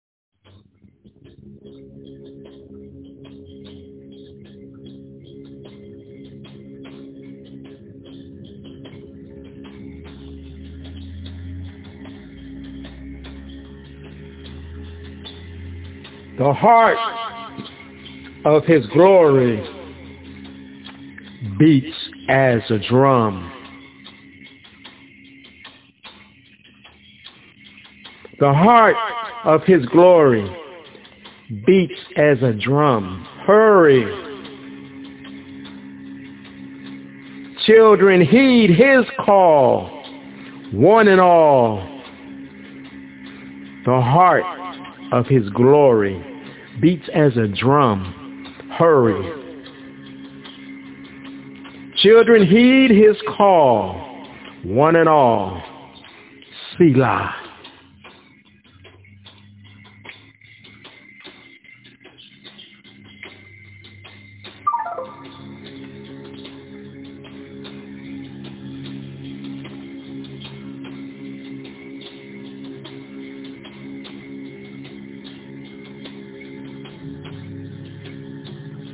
Categories: selah, analogy, confidence, engagement, god, metaphor, spoken word,